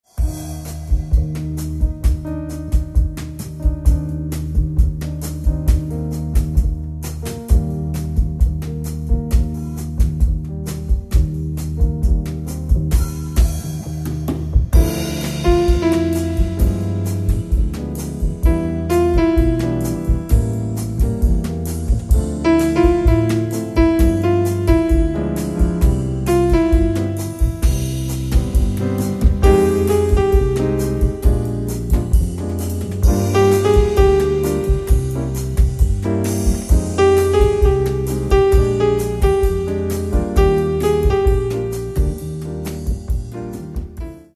Каталог -> Джаз и около -> Сборники, Джемы & Live